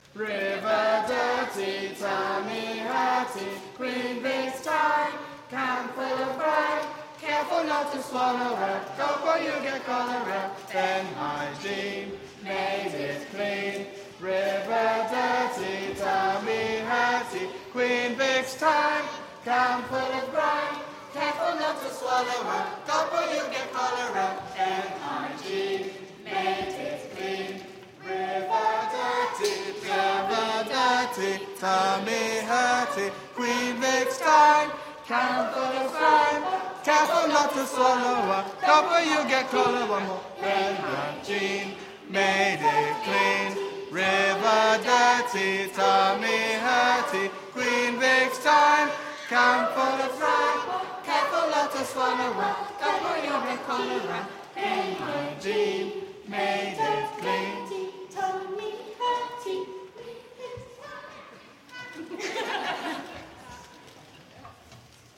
Singing History Concert 2016: River Dirty